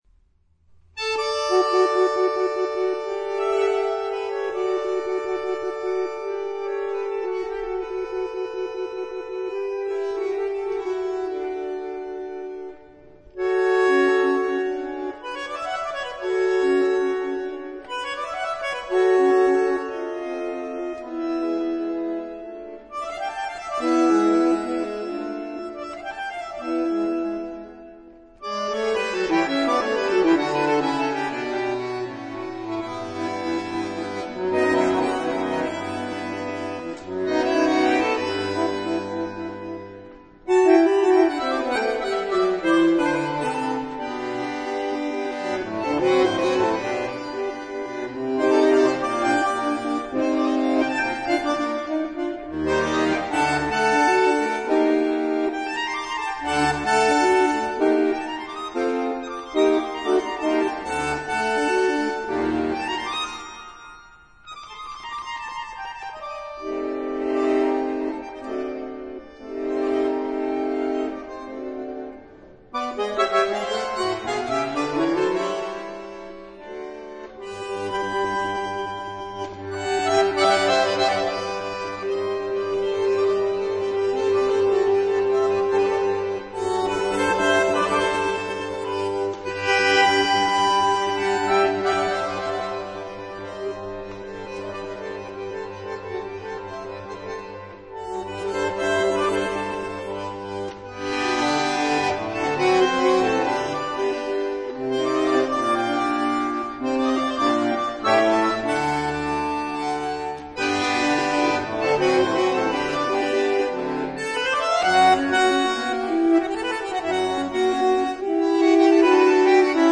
Zijn arrangementen waren complex en zijn belangrijkste stuk was ongetwijfeld Aires Españoles, het eerste solo stuk (1924) voor solo bandoneon.